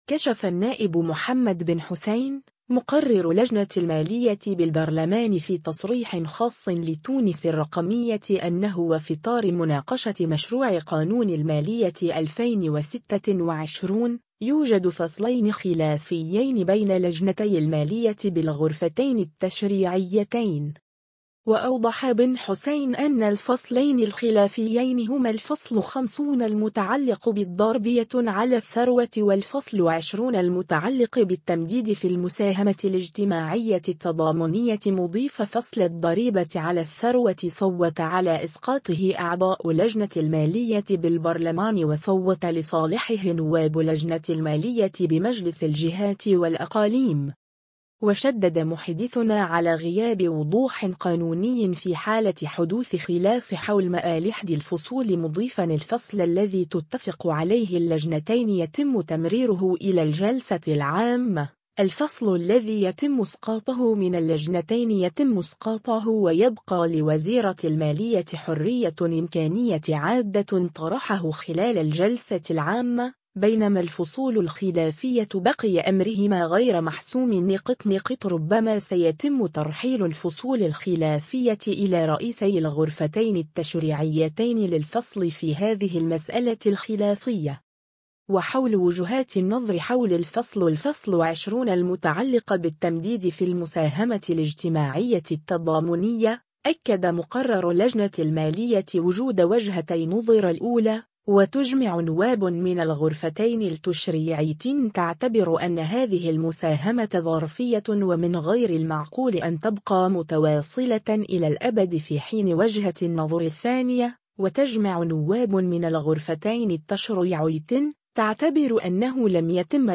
كشف النائب محمد بن حسين، مقرر لجنة المالية بالبرلمان في تصريح خاص لـ”تونس الرقمية” أنه وفي اطار مناقشة مشروع قانون المالية 2026، يوجد فصلين خلافيين بين لجنتي المالية بالغرفتين التشريعيتين.